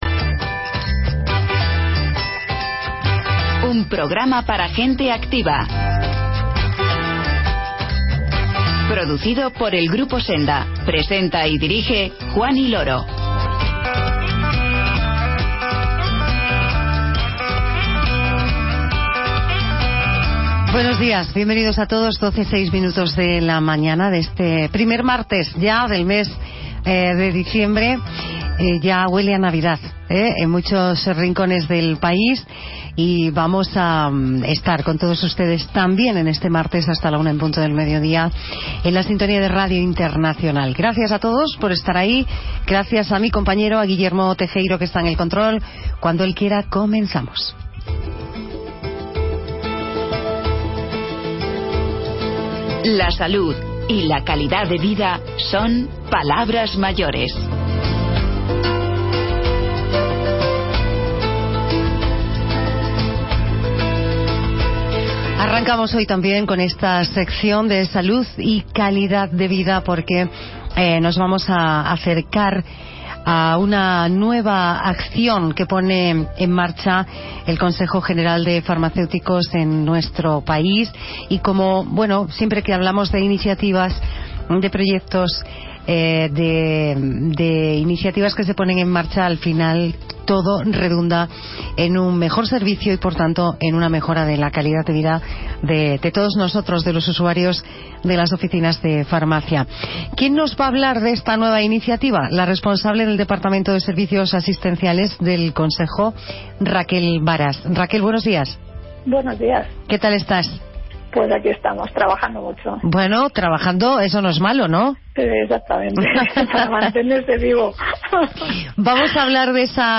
Y Charlamos con el artista Alberto Corazón